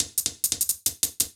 Index of /musicradar/ultimate-hihat-samples/175bpm
UHH_ElectroHatD_175-02.wav